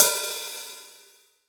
007_HT - Hi-Hat 46.wav